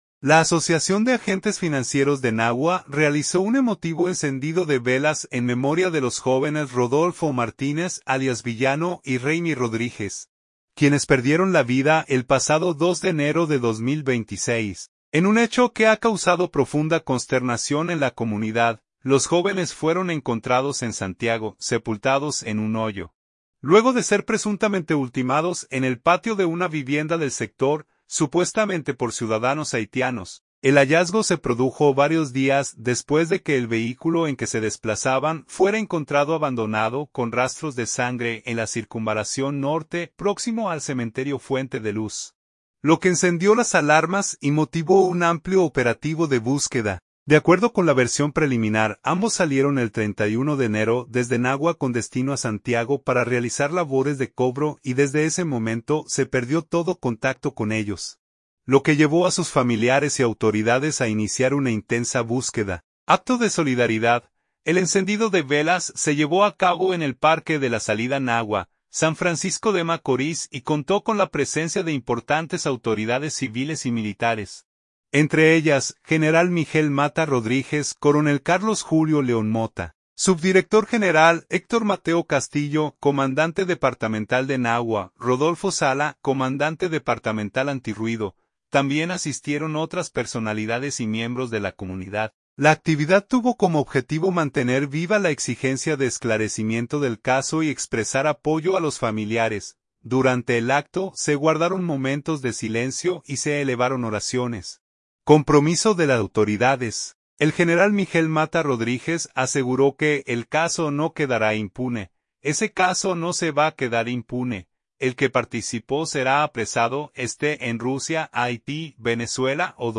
Durante el acto se guardaron momentos de silencio y se elevaron oraciones.